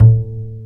Index of /90_sSampleCDs/Roland - Rhythm Section/BS _Jazz Bass/BS _Acoustic Bs